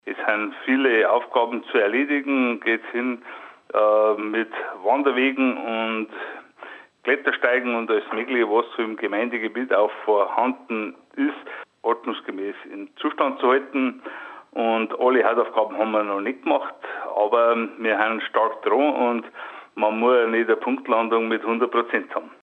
Bürgermeister Josef Loferer: